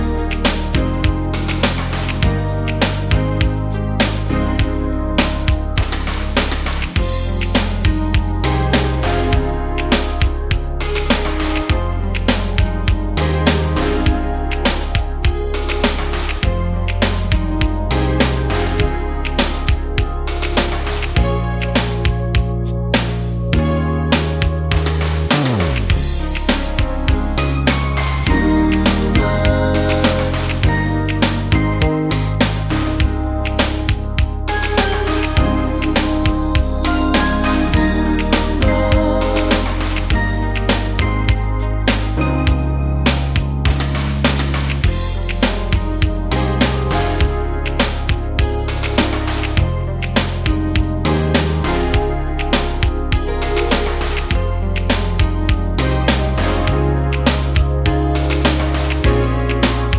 For Solo Male Pop (1980s) 3:53 Buy £1.50